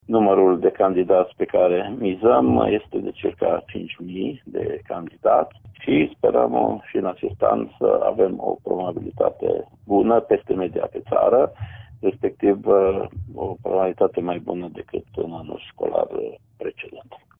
Inspectorul școlar general Ștefan Someșan.